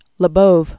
(lə-bōv), William Born 1927.